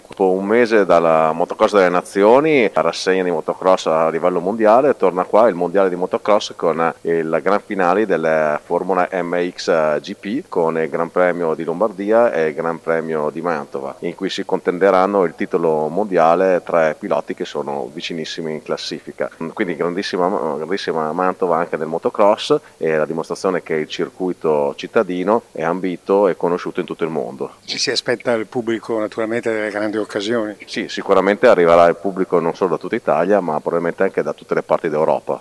L’assessore del Comune di Mantova Iacopo Rebecchi:
Interviste
Iacopo-Rebecchi-assessore-alla-Polizia-Locale-di-Mantova.mp3